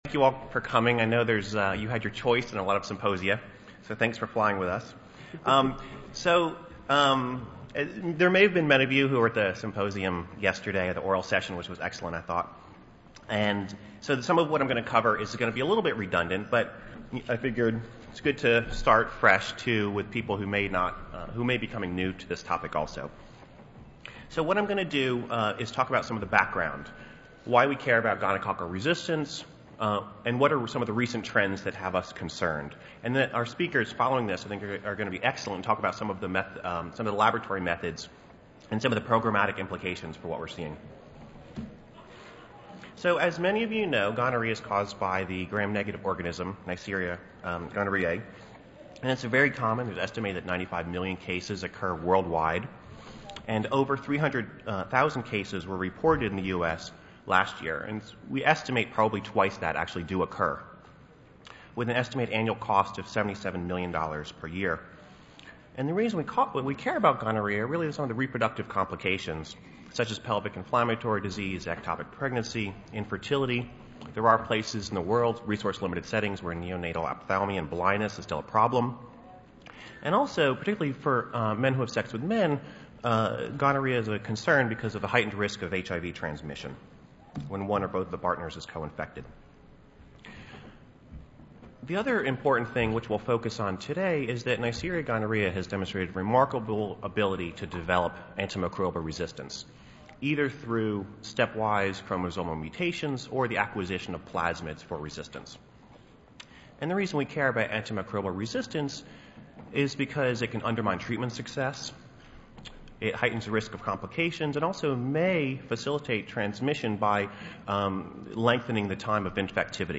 Abstract: The Public Health Importance of N. Gonorrhoeae Resistance and Recent Surveillance Trends (2012 National STD Prevention Conference)